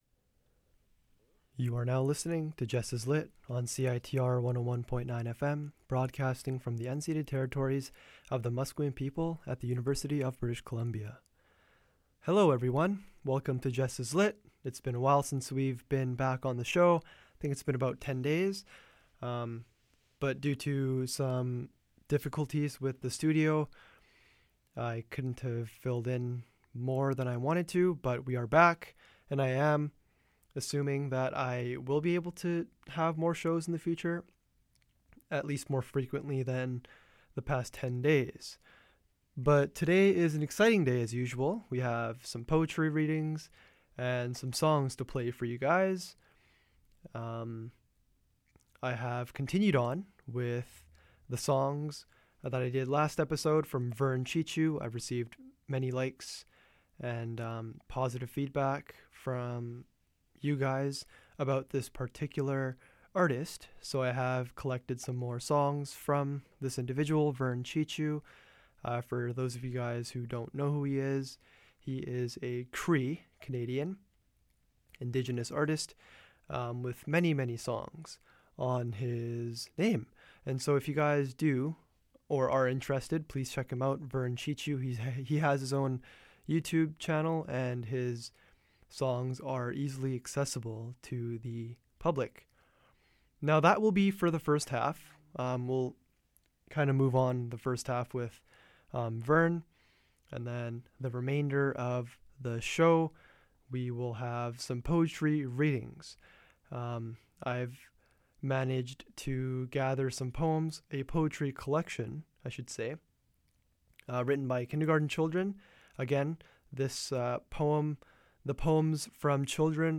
The second half of the show will be a poetry reading session. I will read children's poetry from the UK, while giving my thoughts and opinions on several of the works.